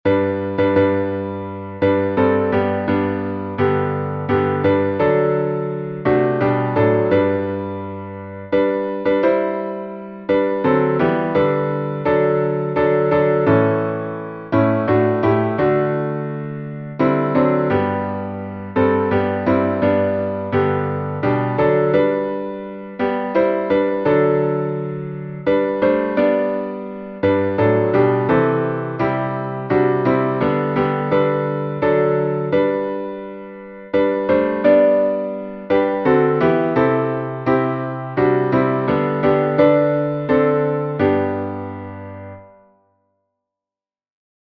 salmo_119_1_8B_instrumental.mp3